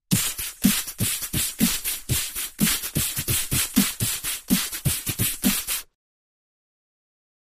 Human Beat Box, Aggressive Beat, Type 1